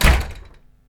Door Slam Sound
household
Door Slam